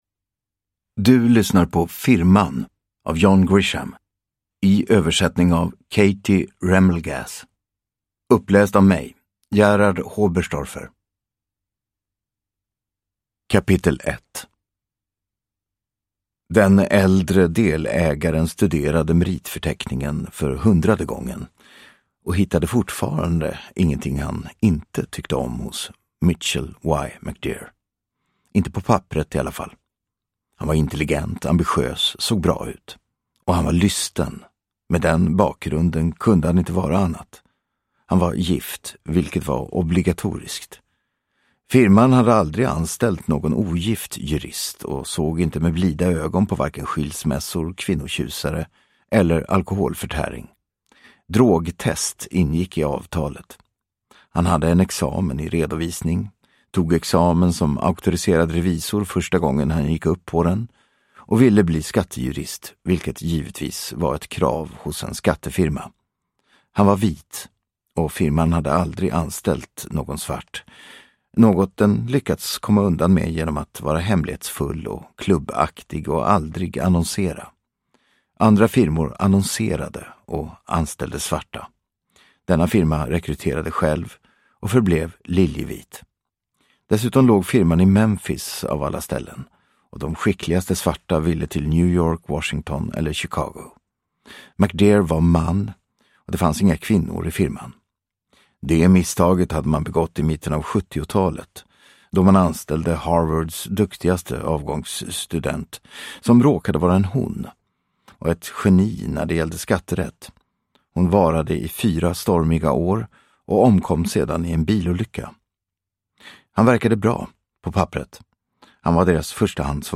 Uppläsare: Gerhard Hoberstorfer